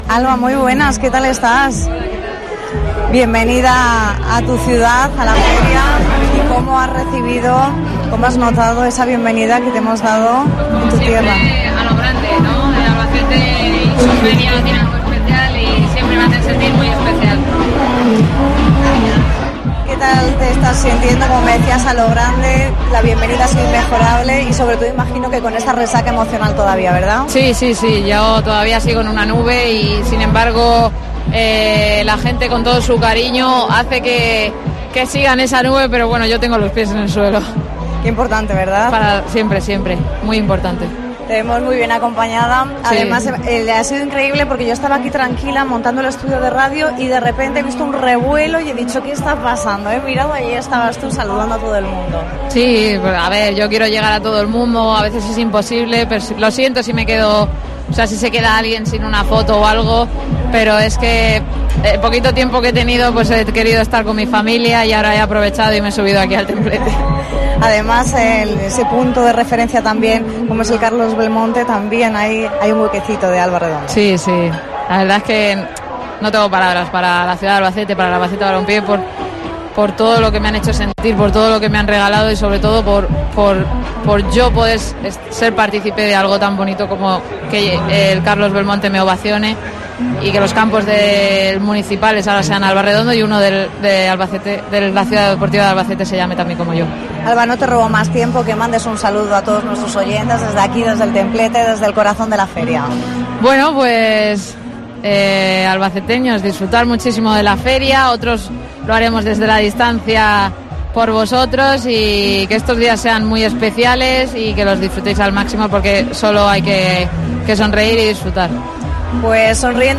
ENTREVISTA COPE
La campeona del mundo ha pasado hoy por el Templete, donde hemos realizado el programa especial de este martes 12 de septiembre